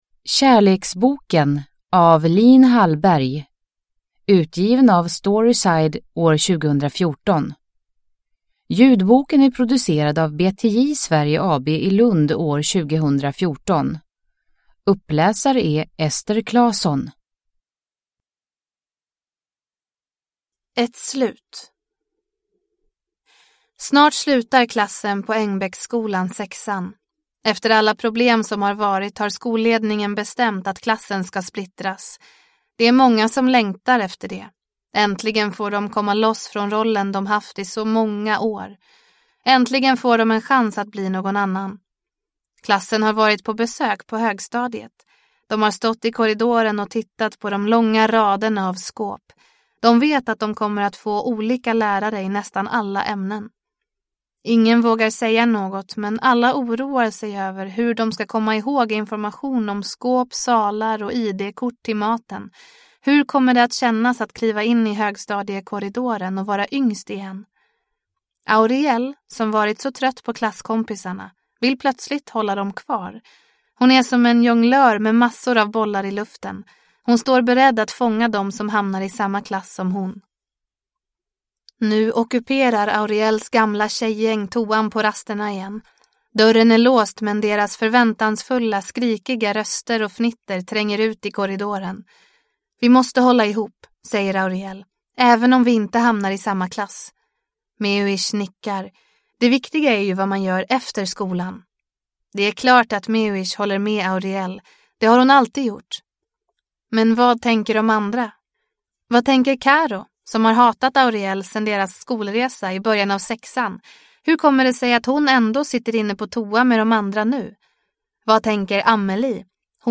Kärleksboken – Ljudbok